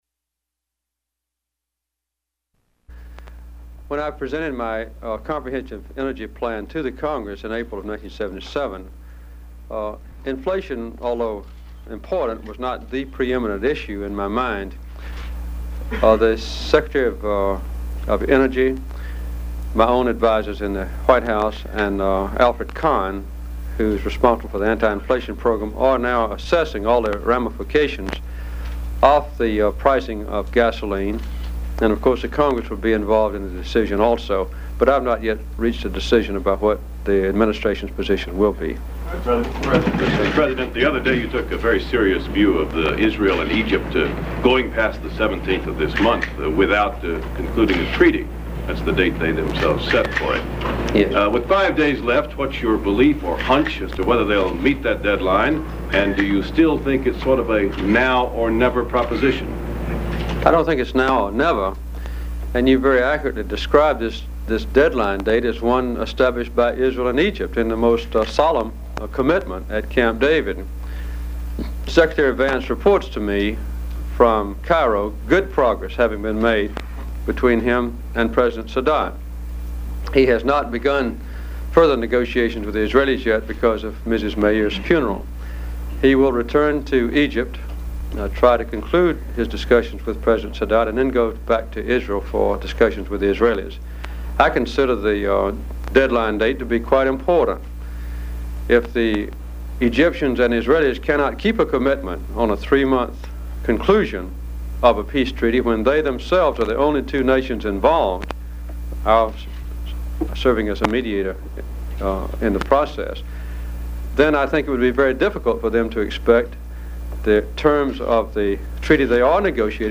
Jimmy Carter gives a press conference on energy, Middle East, Congress, Nicaragua, Namibia, political conventions, national health care, oil prices, economy, Ted Kennedy, Iran, and SALT
Broadcast on ABC TV, December 12, 1978.